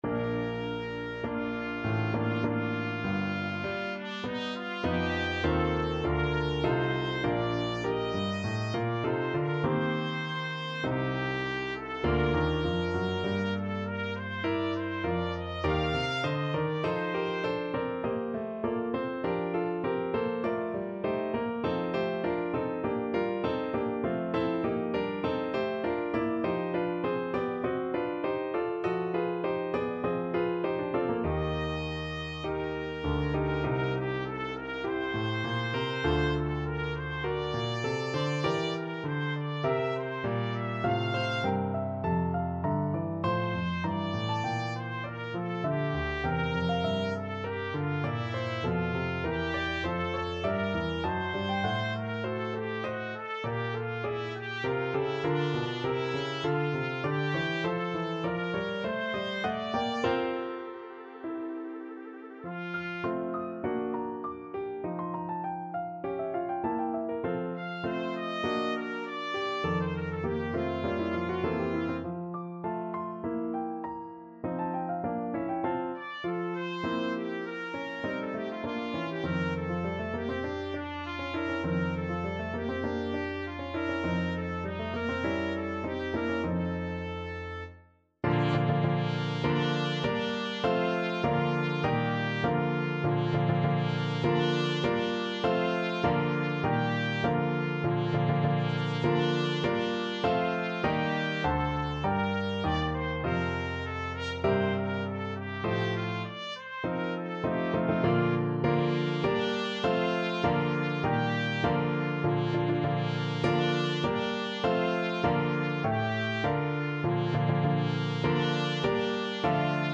Trumpet
4/4 (View more 4/4 Music)
Molto moderato mosso
Arrangement for Trumpet and Piano
Bb major (Sounding Pitch) C major (Trumpet in Bb) (View more Bb major Music for Trumpet )
Classical (View more Classical Trumpet Music)